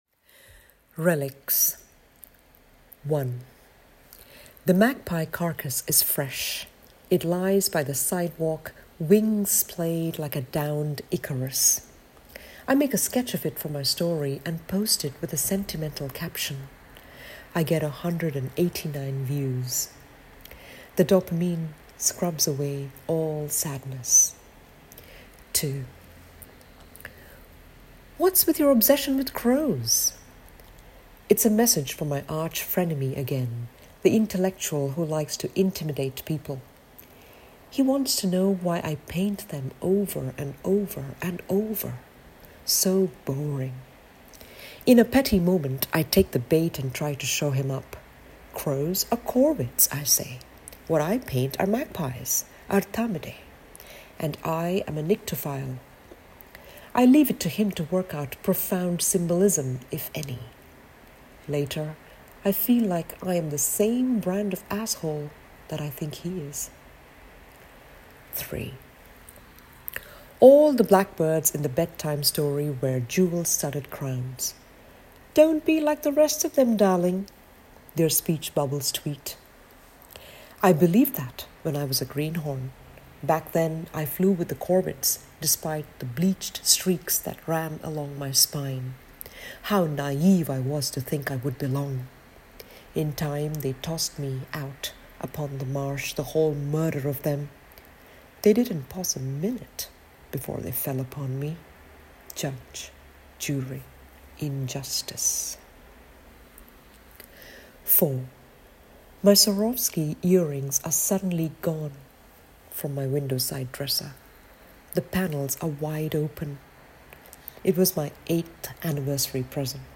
Or you can let them read to you!